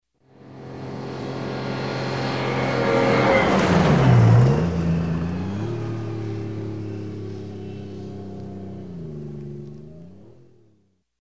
Звуки военной техники
Бронетранспортер медленно проезжает мимо